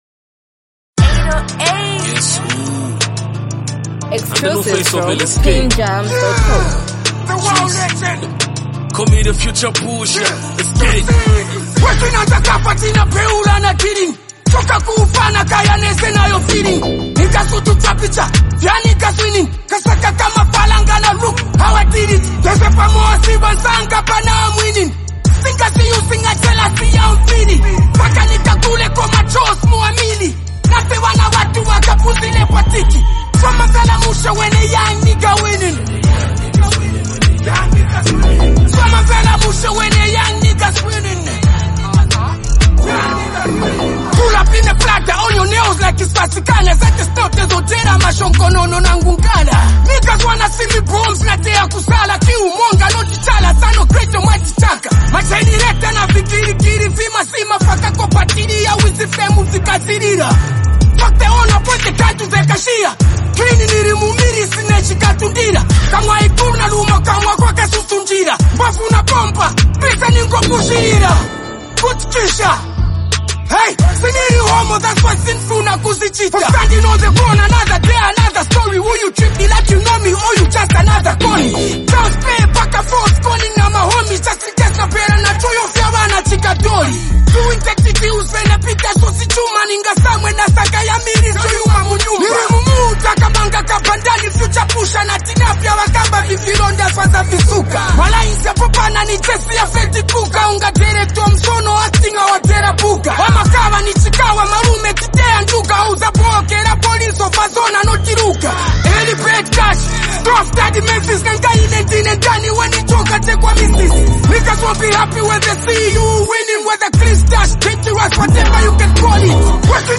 an uplifting and motivational track
a catchy and melodic hook